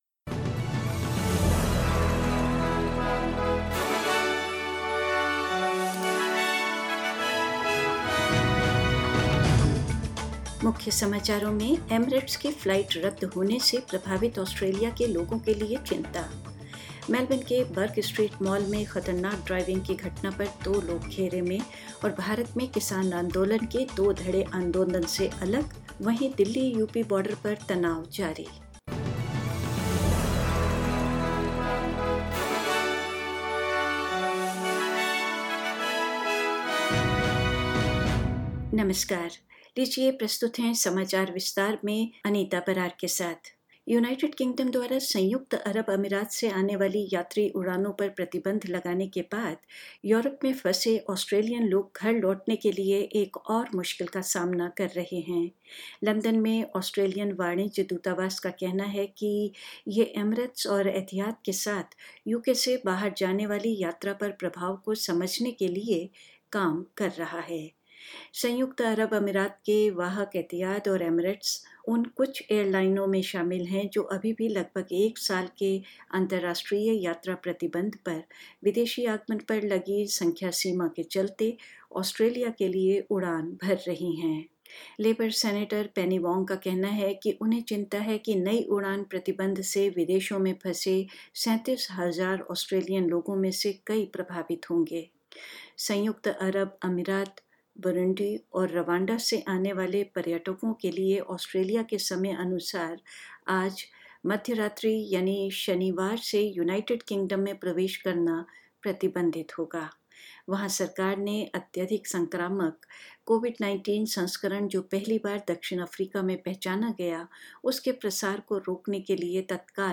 News in Hindi: Queensland Premier tells Federal Government to take responsibility for quarantine